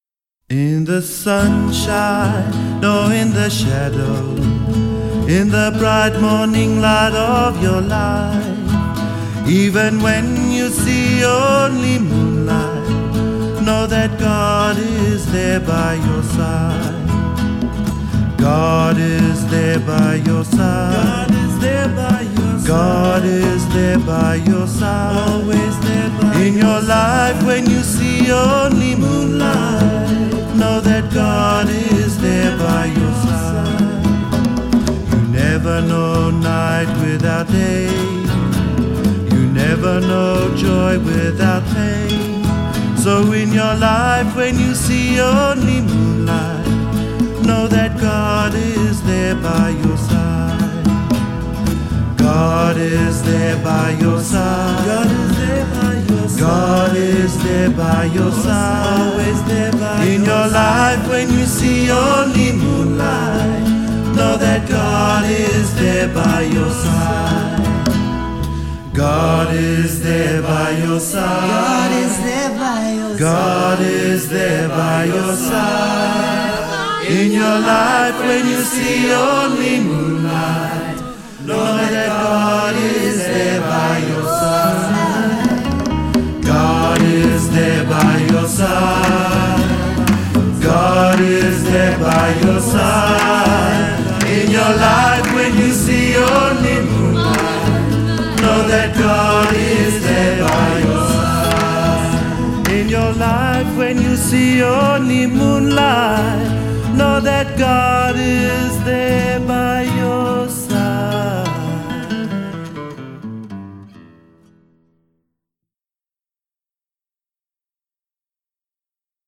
1. Devotional Songs
Major (Shankarabharanam / Bilawal)
8 Beat / Keherwa / Adi
4 Pancham / F
1 Pancham / C
Lowest Note: p / G (lower octave)
Highest Note: D2 / A